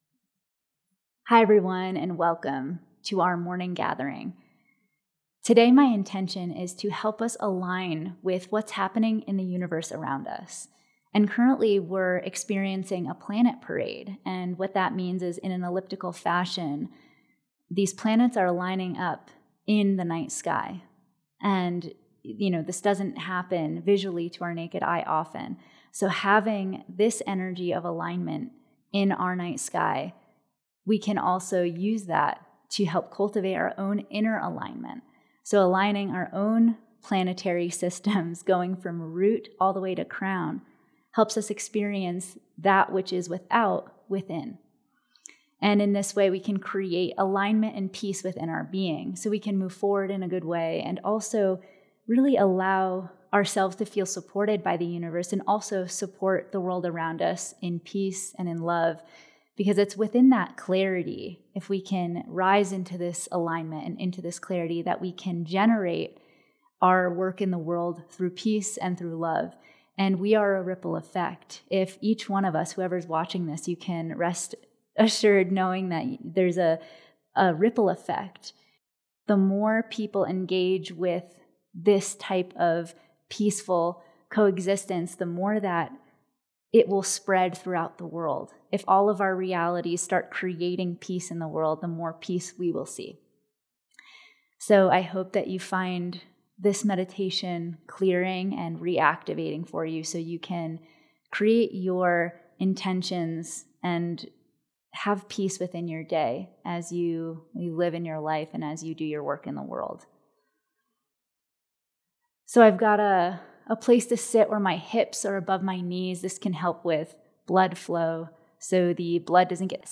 A guided practice to awaken and balance your chakras, moving energy through your body to create alignment, vitality, and spiritual connection.